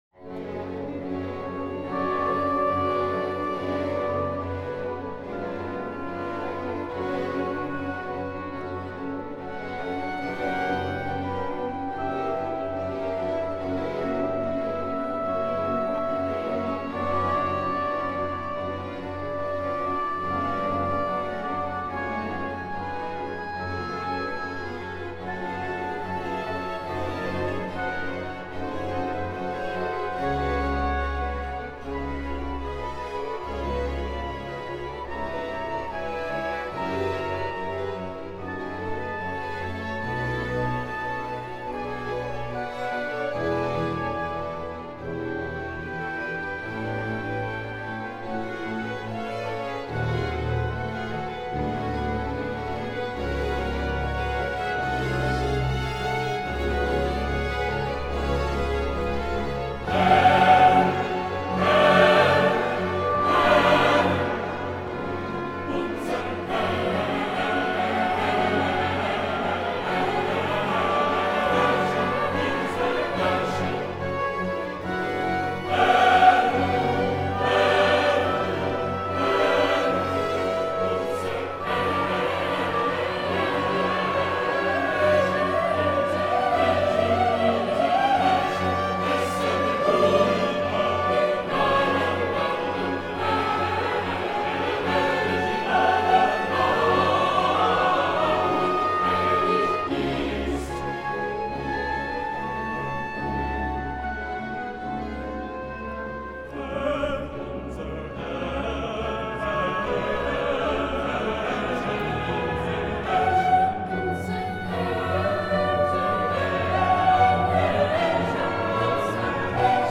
Chor